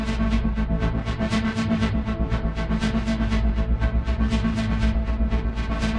Index of /musicradar/dystopian-drone-samples/Tempo Loops/120bpm
DD_TempoDroneB_120-A.wav